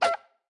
Media:Chicken_baby_atk_6.wav 攻击音效 atk 初级形态攻击音效
Chicken_baby_atk_3.wav